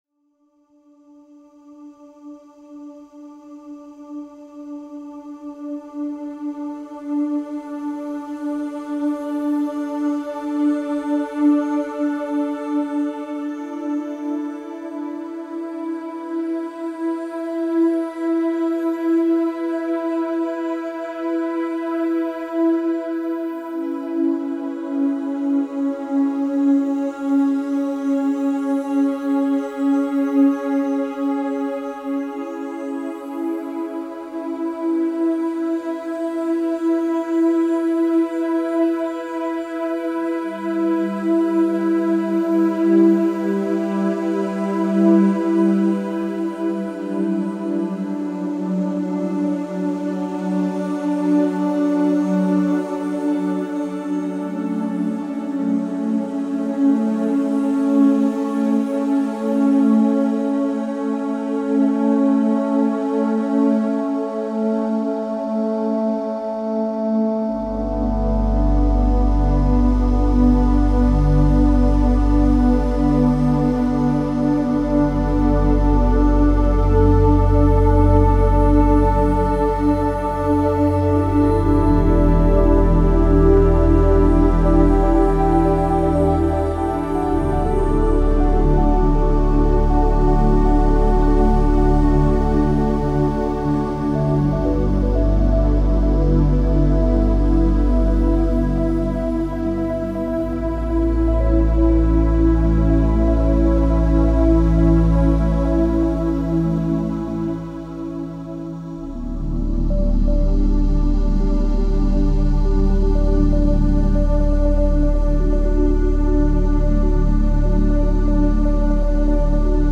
Calming music for meditation, relaxation, and stress relief.